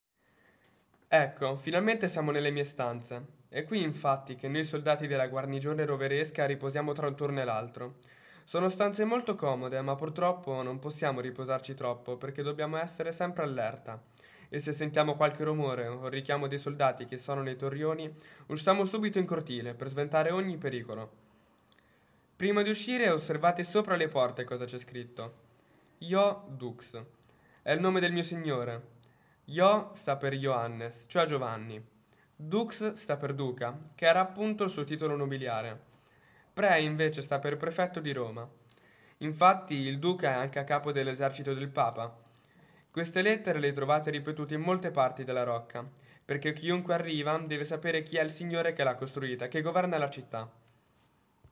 Audioguida 0-12
Ascolta Riccardino, il cavaliere della Rocca